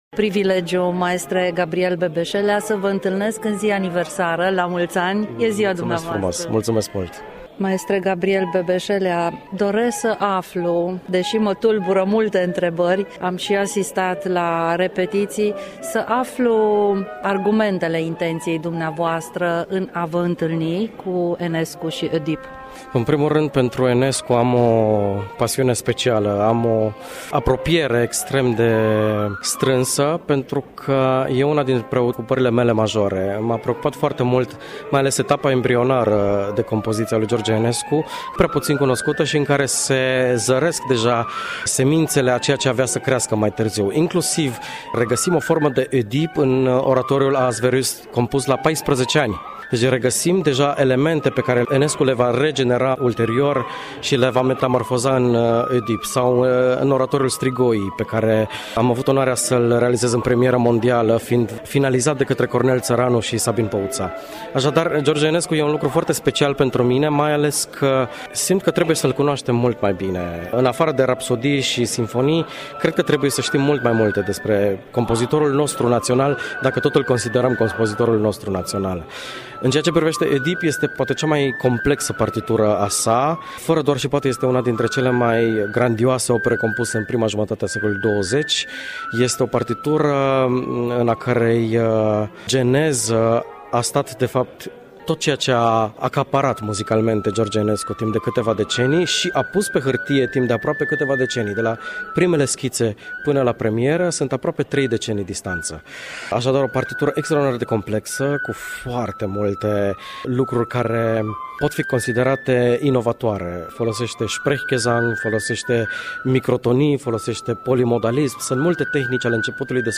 interviu realizat la Filarmonica „Banatul” Timișoara